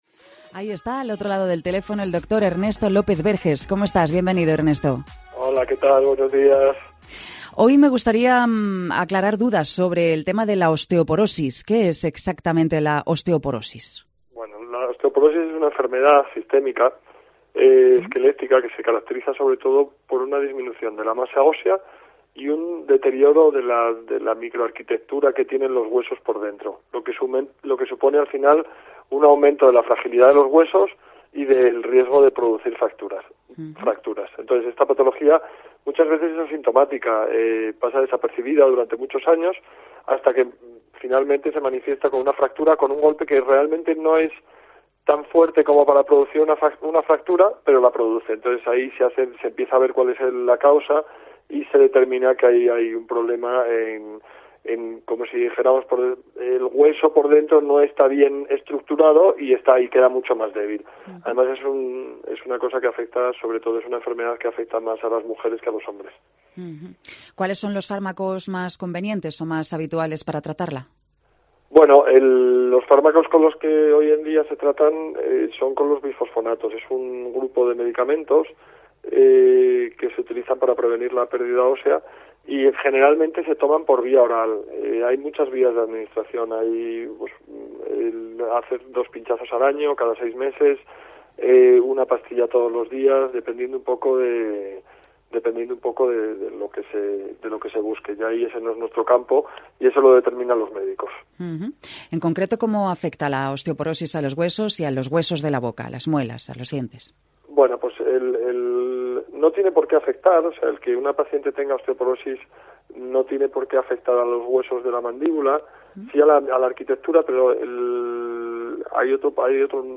En su paso por Cope Salamanca